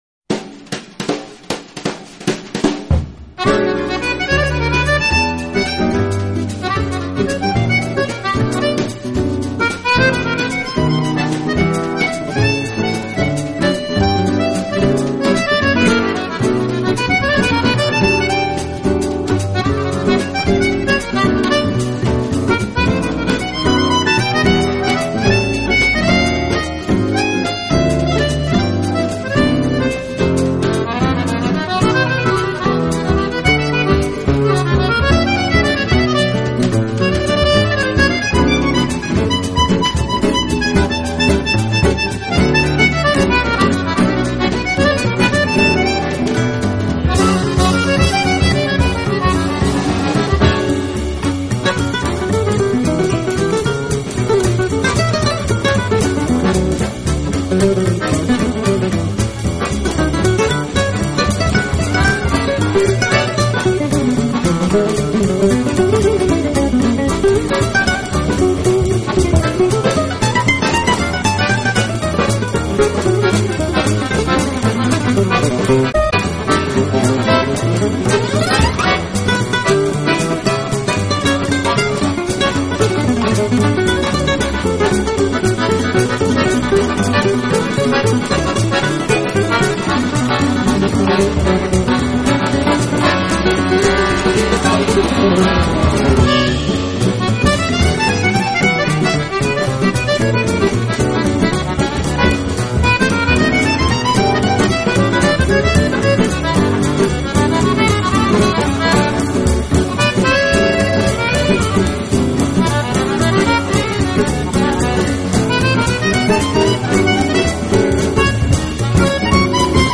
【爵士手风琴】
类型: Jazz, Tango, Instrumental
奏热烈奔放而又不失温柔浪漫。